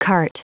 Prononciation du mot cart en anglais (fichier audio)
Prononciation du mot : cart